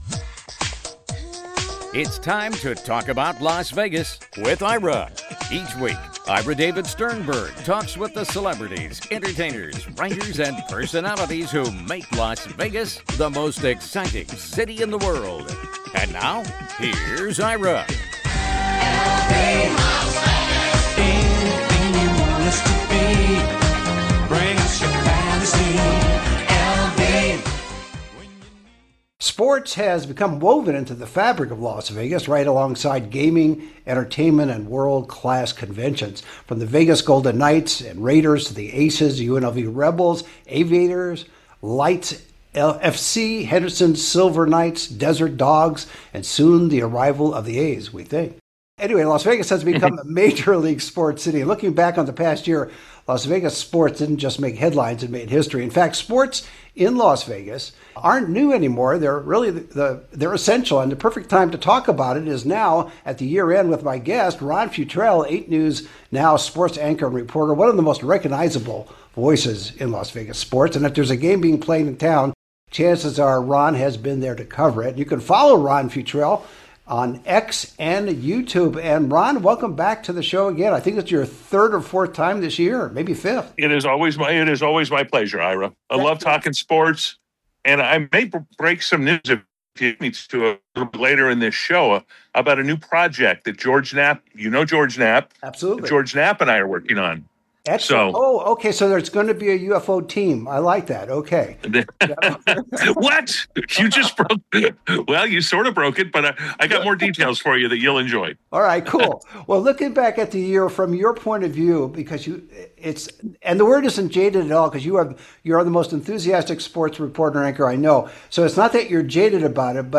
talks with the celebrities, entertainers, writers, and personalities who make Las Vegas the most exciting city in the world.